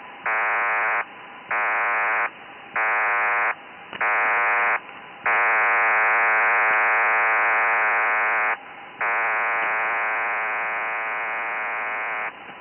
PACTOR-IV
PACTOR-4 running 56.25 Bd 2-tone Chirp modulated DBPSK PACTOR-4 running 112.5 Bd Spread-16 modulated DQPSK PACTOR-4 running 225 Bd Spread-8 modulated DQPSK PACTOR-4 running 1800 Bd DBPSK modulated serial tone
PACTOR-IV_112-5_DQPSK.WAV